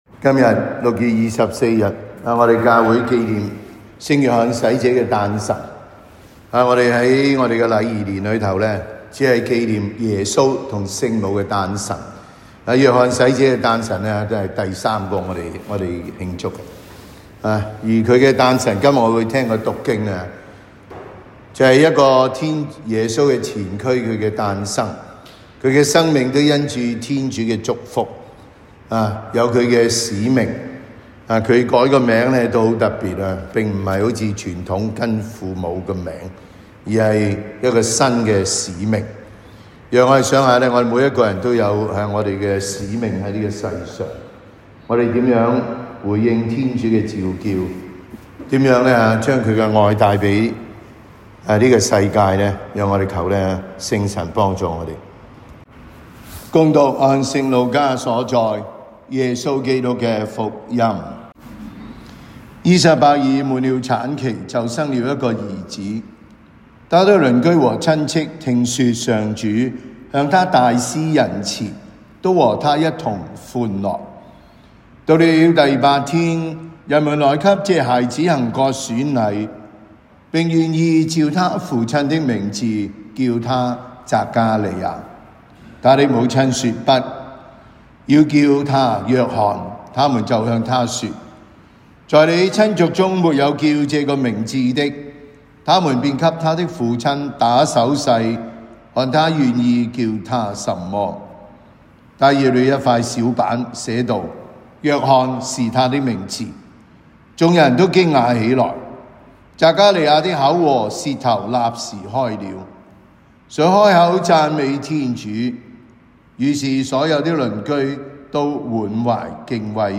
感恩祭講道